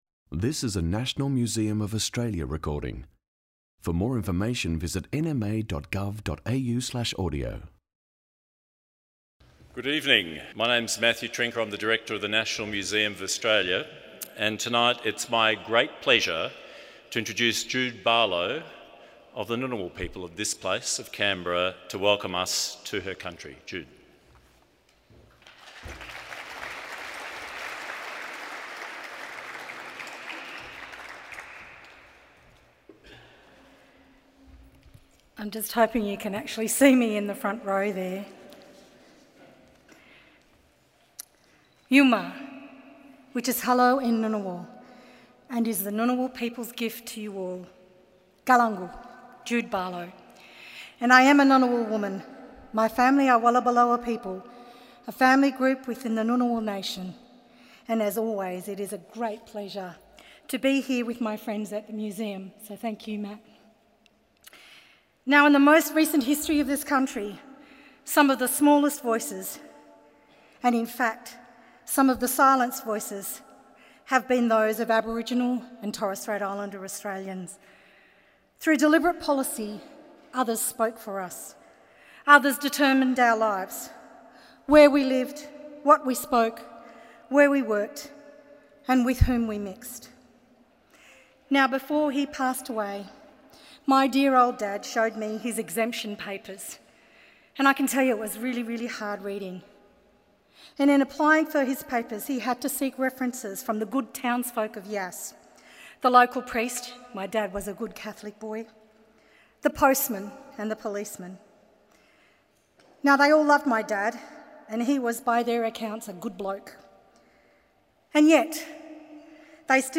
It's Time for True Constitutional Recognition argues renowned Aboriginal activist and community leader Noel Pearson in the inaugural Australia Speaks address at the National Museum of Australia.
australia-speaks-noel-pearson.mp3